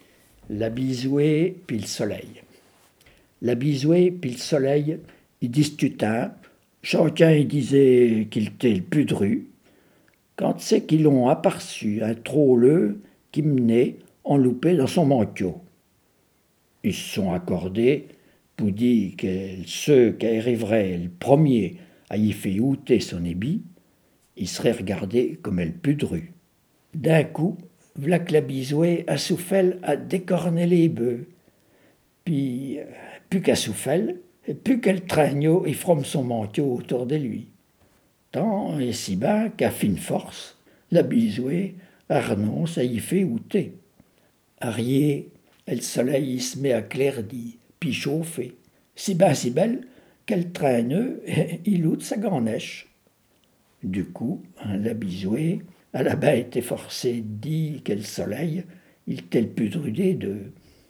la-bise-et-le-soleil-en-poyaudin.wav